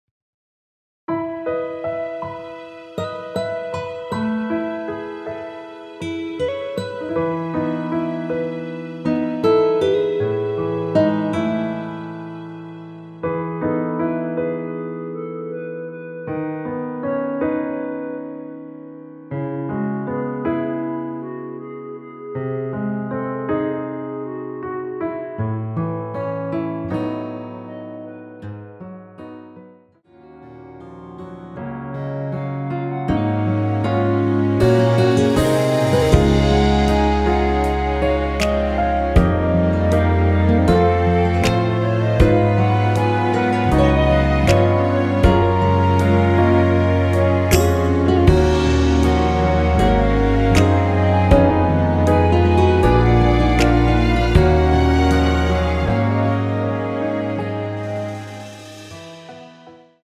원키 멜로디 포함된 MR입니다.(미리듣기 확인)
멜로디 MR이라고 합니다.
앞부분30초, 뒷부분30초씩 편집해서 올려 드리고 있습니다.
중간에 음이 끈어지고 다시 나오는 이유는